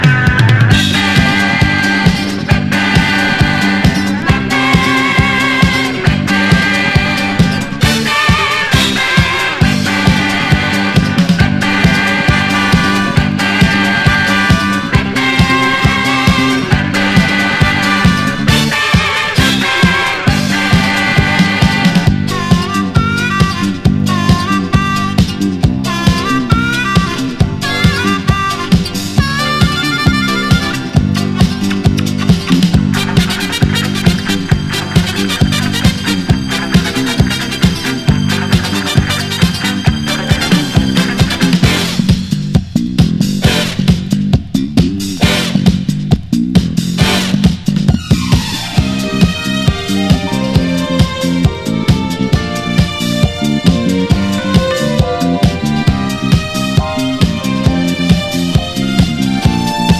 ROCK / 60'S / BRITISH BEAT
改めて聴き直してほしいかっこいいブリティッシュ・ビート！